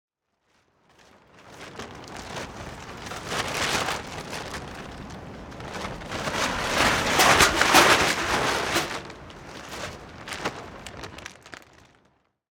tarp.R.wav